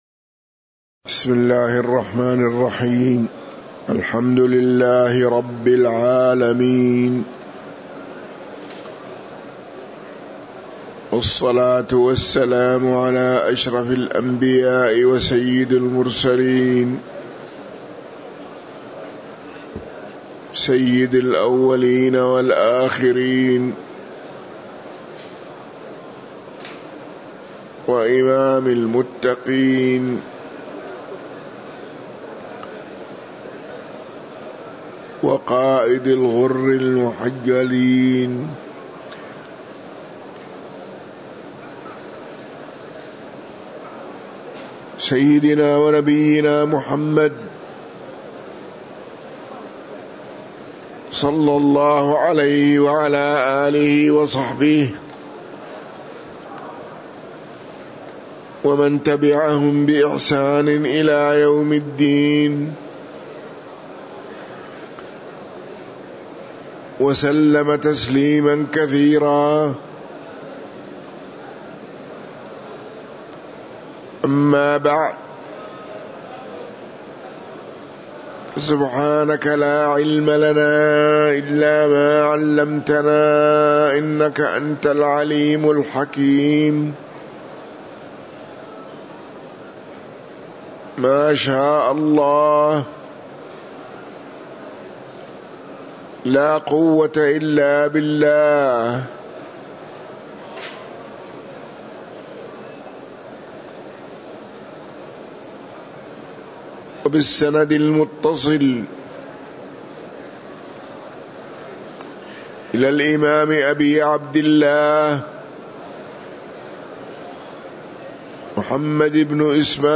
تاريخ النشر ٤ رمضان ١٤٤٤ هـ المكان: المسجد النبوي الشيخ